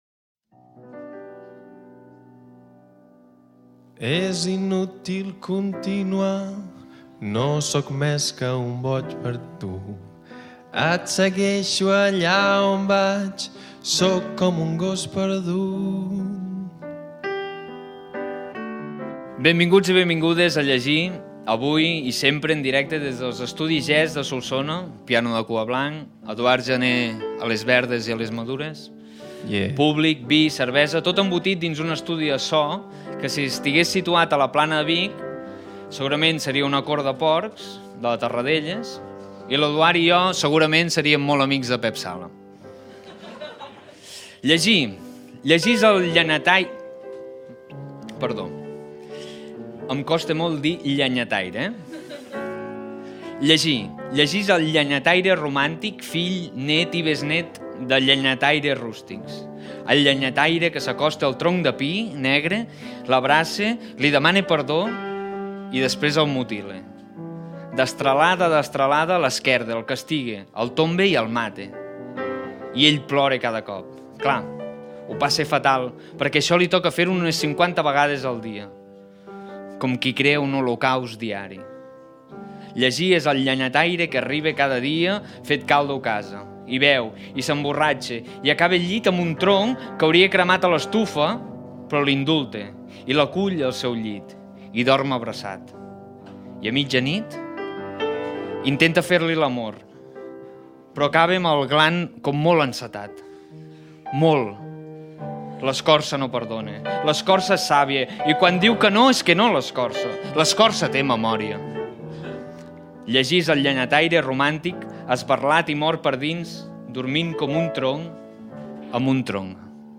Cultura
Presentador/a